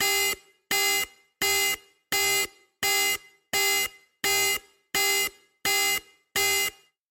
Звуки охранной сигнализации